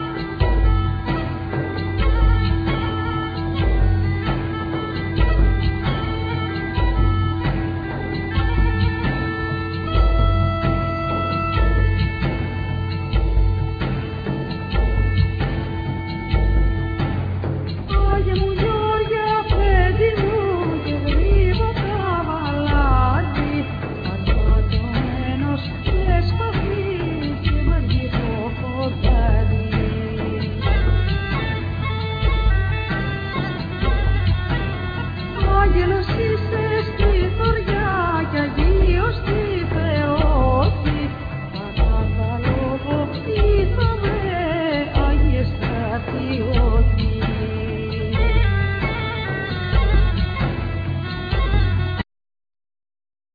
Loud,Mandola,Mandolin,Jura,Percussions
Vocals
Kaval,Gajda
Keyboards
Kemenche